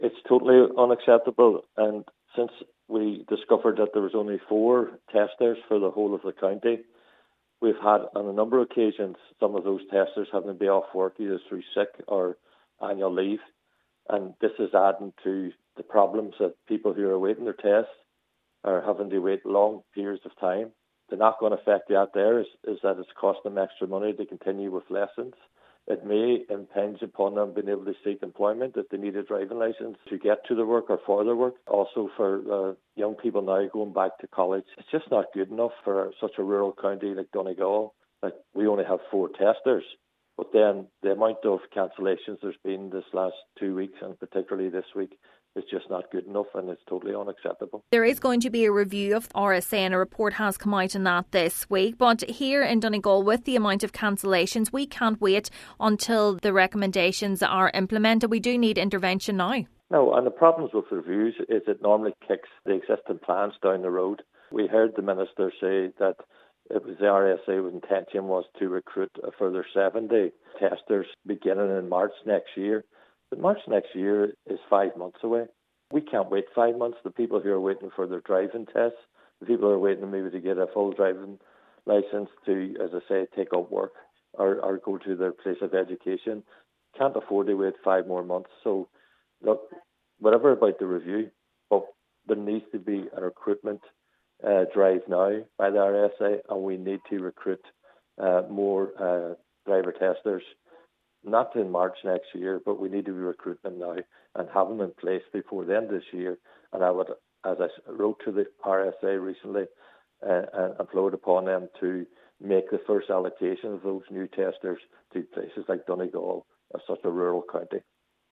Councillor Gerry McMonagle says it’s vital a recruitment driver for testers is fast-tracked: